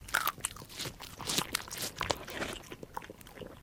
Divergent / mods / Soundscape Overhaul / gamedata / sounds / monsters / pseudodog / eat_0.ogg
eat_0.ogg